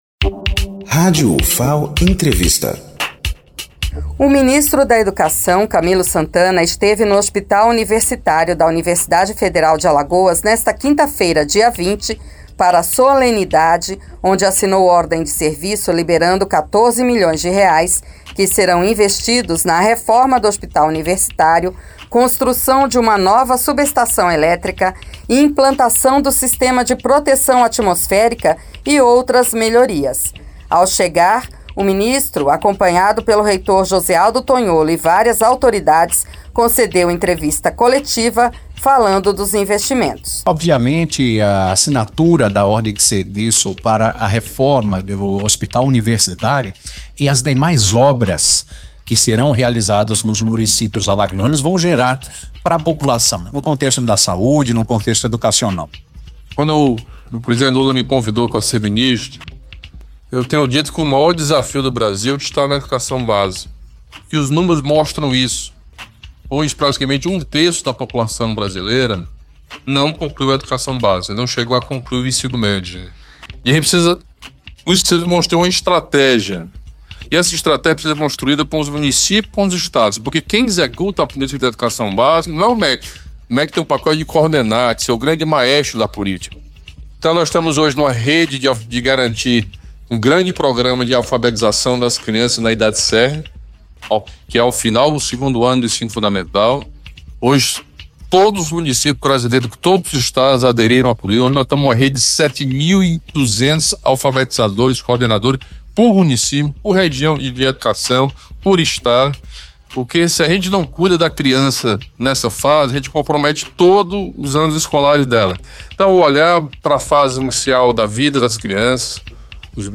Camilo Santana, Ministro da Educação, concedeu entrevista coletiva e falou sobre os investimentos
No hall de entrada do HU, logo na chegada da comitiva, foi concedida uma entrevista coletiva na qual o ministro destacou a importância da iniciativa para a melhoria da infraestrutura hospitalar e anunciou novos investimentos para os campi da Ufal.